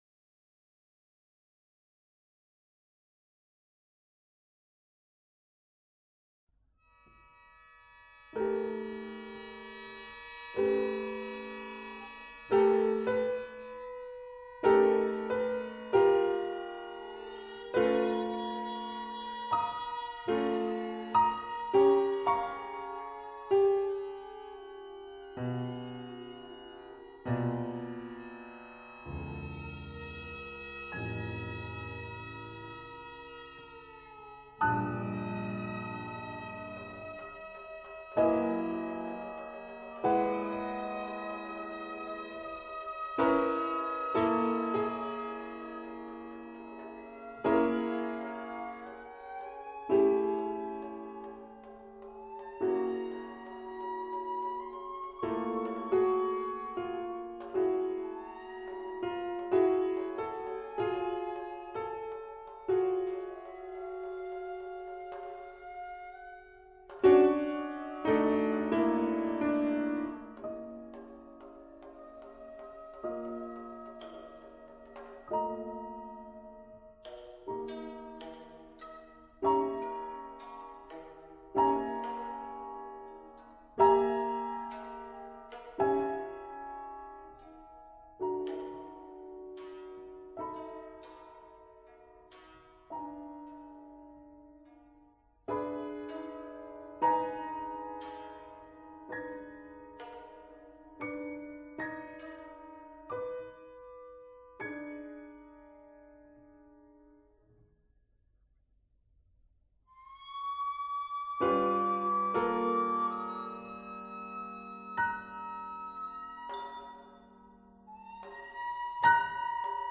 MP3: (CREATING) COUPLED IMPROVISATIONS;
RaddoppiocumMusicaGagaku.mp3